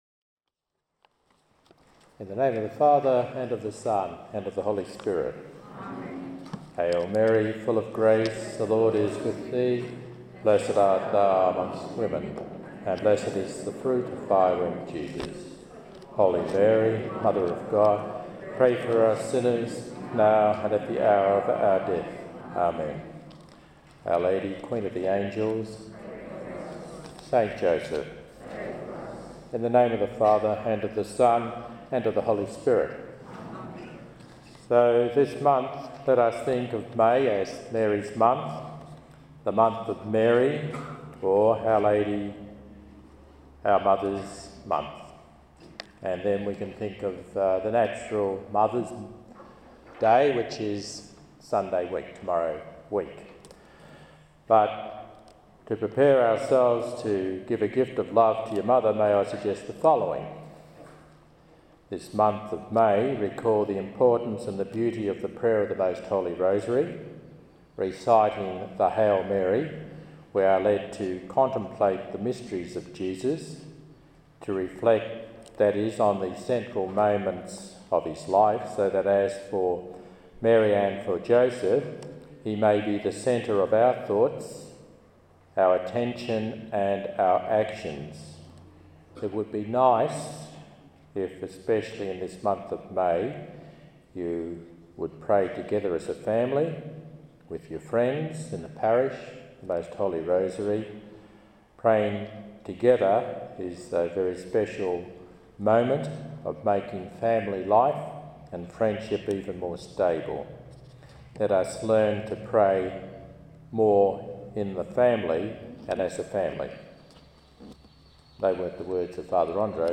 during the "Day With Mary" held at Our Lady of the Mission Parish in Craigie, Western Australia on 4 May 2013.